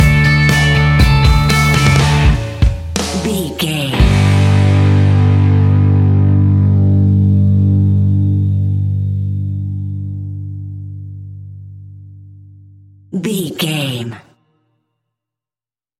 Ionian/Major
D
energetic
uplifting
instrumentals
upbeat
groovy
guitars
bass
drums
piano
organ